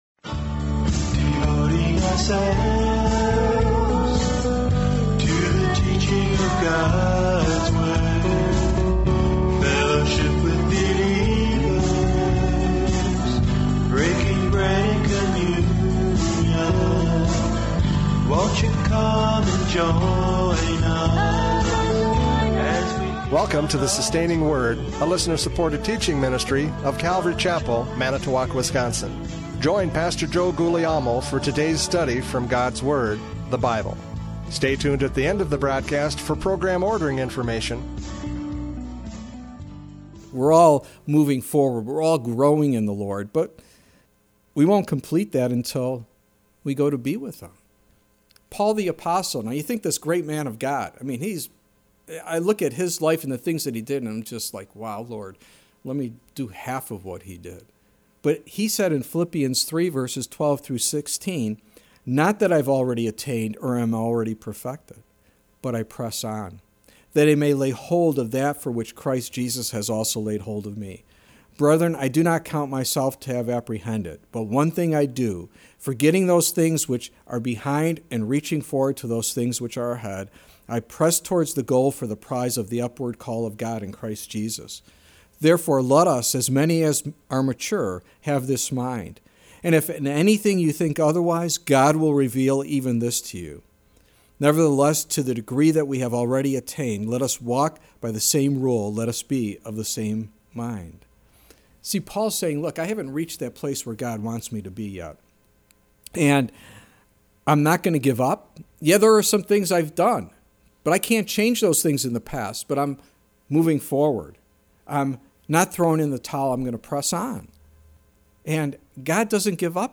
John 4:1-10 Service Type: Radio Programs « John 4:1-10 A Heart That Is Open!